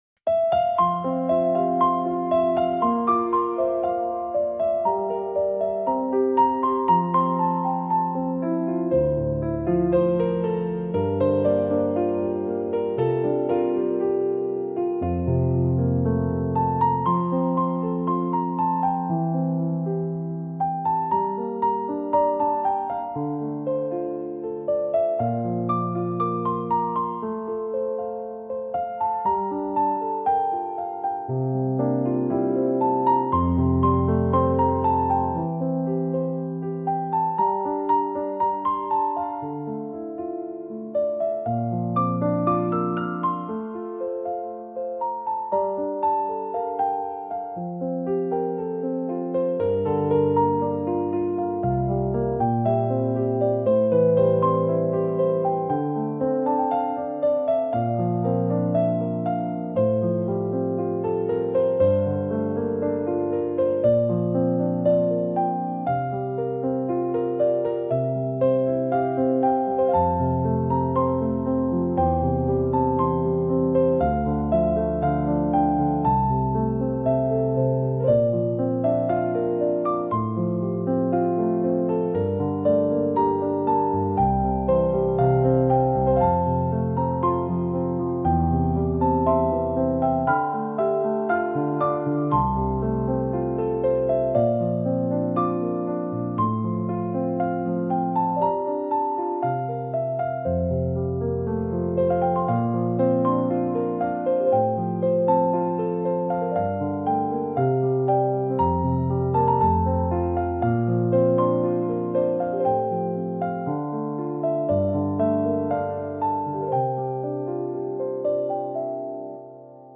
優しいピアノバラード曲・ボーカル無し※ピアノオンリー曲です！
バラード 感動 切ない 優しい